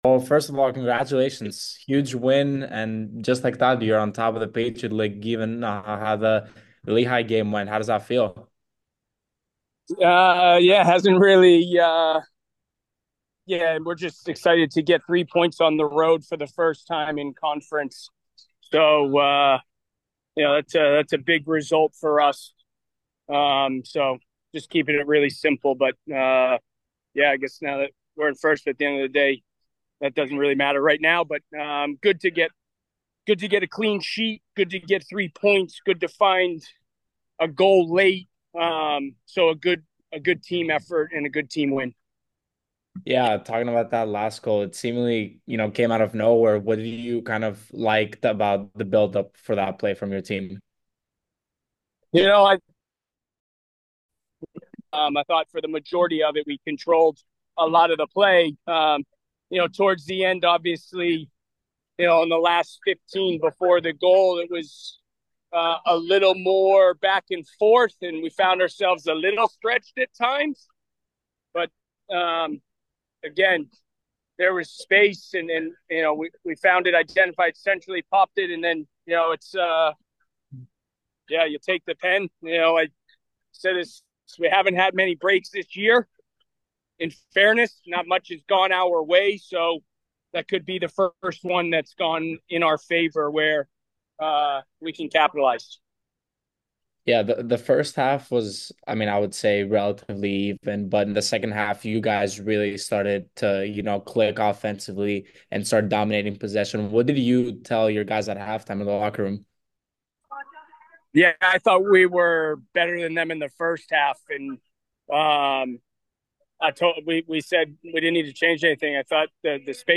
Colgate Postgame Interview